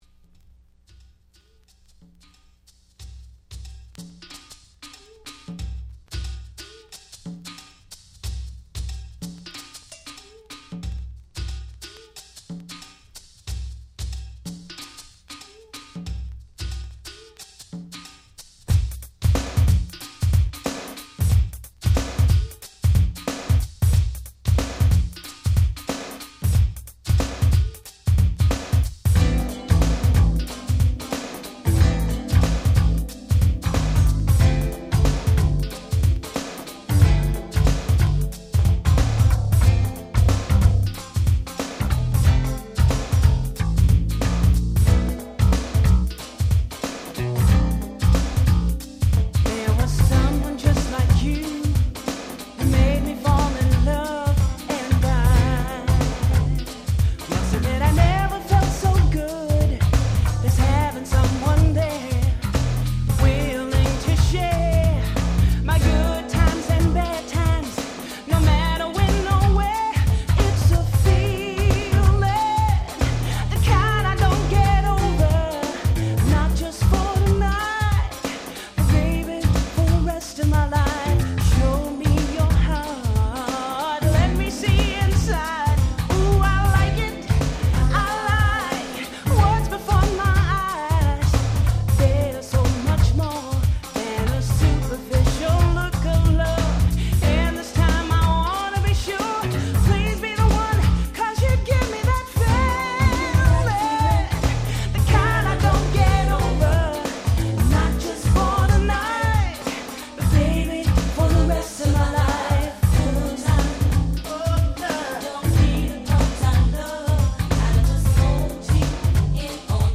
90' Nice New Jack Swing/R&B !!
女性Vocalの切なく哀愁漂う超マイナーNJS !!